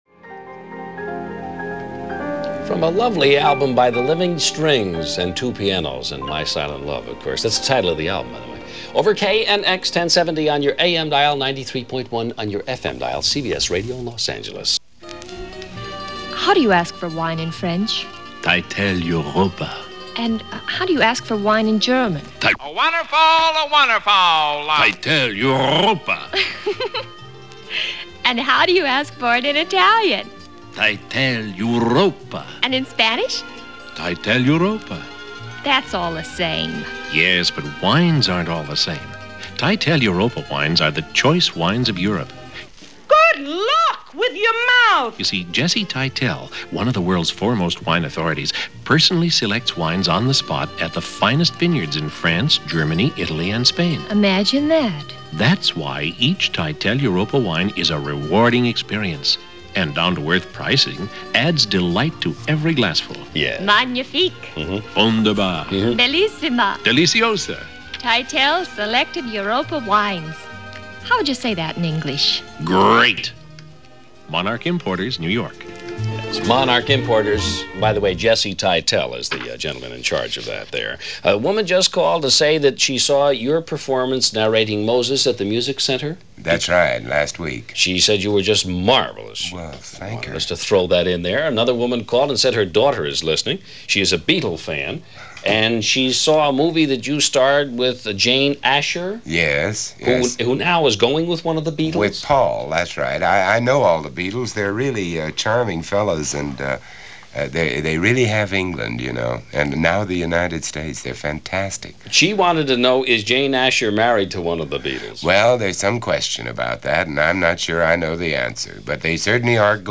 On this particular show, an excerpt from the broadcast of April 14, 1965, Crane had the iconic actor Vincent Price and noted Astrologer Sydney Omar as guests. Price is promoting an upcoming appearance for a local theatre production of Peter Pan , and also offering a few tidbits about his knowledge of The Beatles . Sydney Omar talks about his new book and tosses out a few predictions. It’s a typical talk show, one which keeps running gags and music liberally dispersed throughout the show.